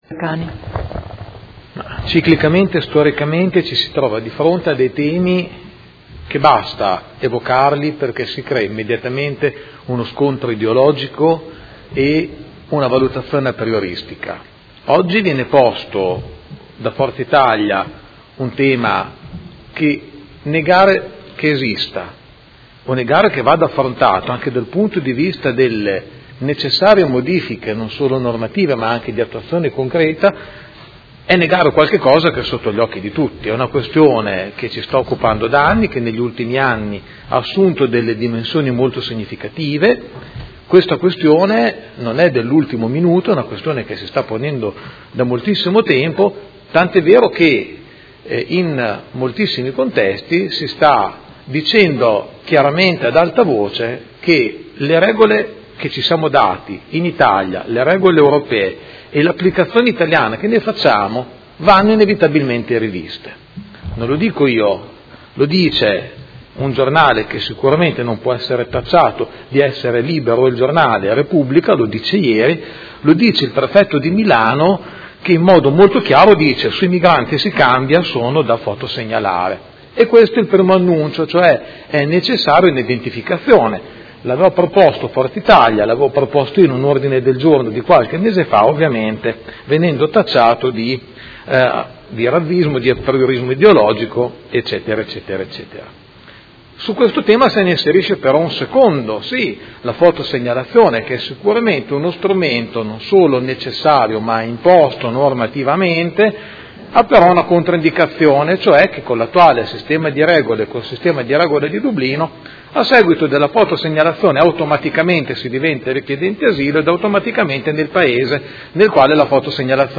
Seduta del 16/02/2017. Dibattito su Mozione presentata dal Gruppo Forza Italia avente per oggetto: Le nostre proposte per l’emergenza immigrazione e per la sicurezza